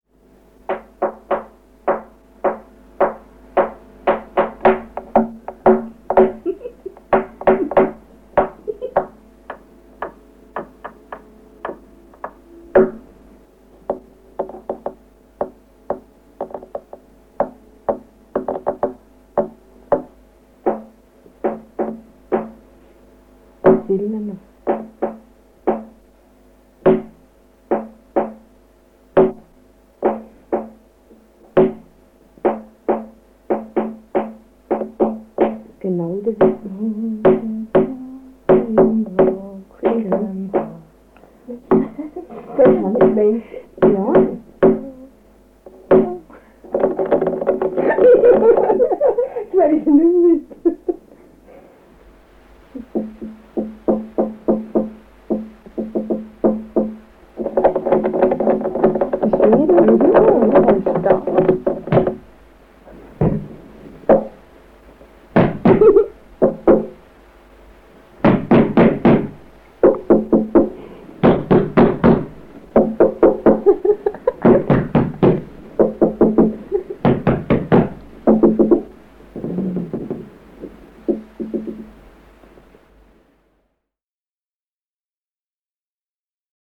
09 The rapping ghost, Thun, Switzerlan.mp3